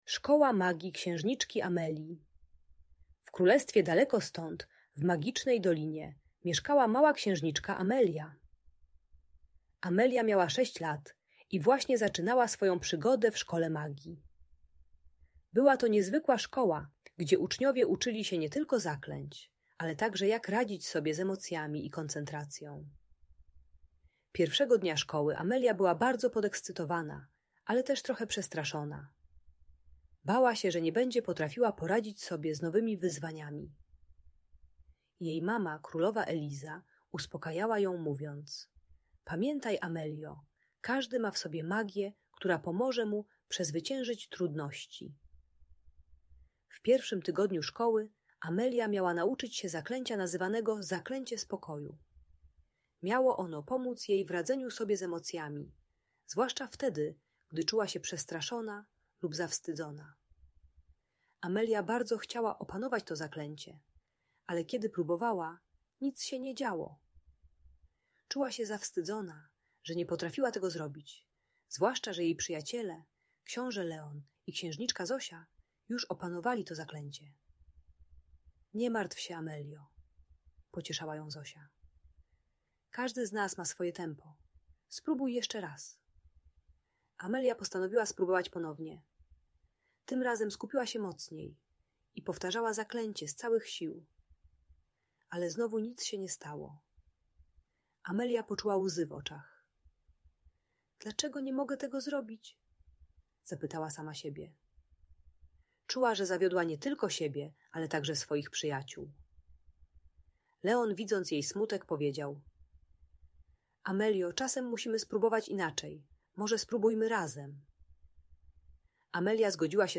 Historia księżniczki Amelii i jej szkoły magii - Lęk wycofanie | Audiobajka